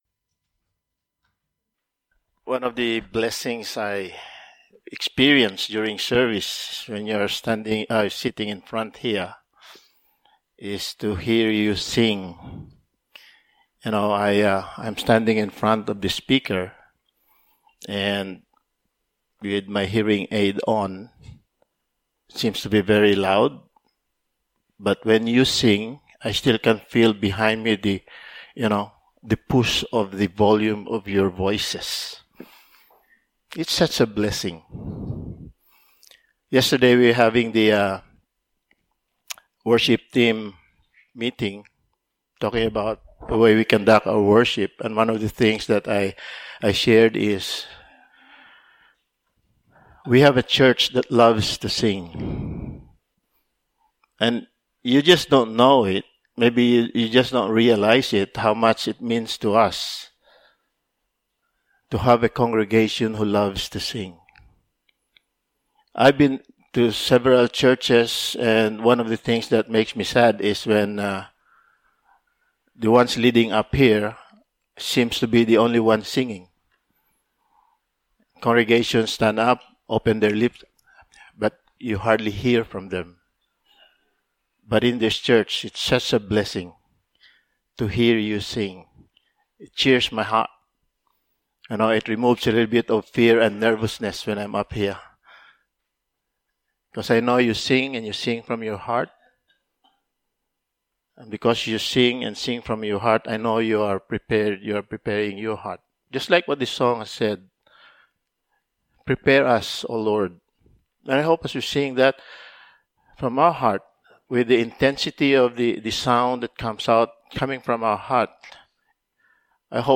John 15:16 Service Type: Sunday Morning « Reasons to Rejoice O Lord